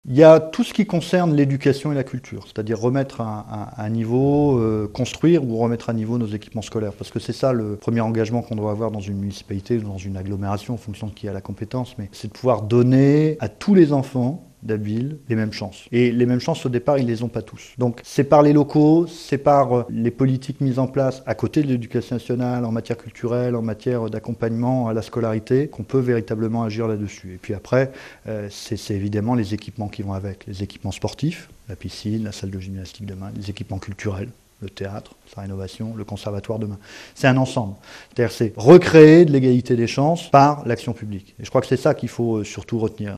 Dans des salons d'honneur pleins à craquer, Nicolas Dumont a présenté hier soir ses vœux aux abbevillois. Les derniers, car l'élu ne se représente pas aux prochaines élections municipales.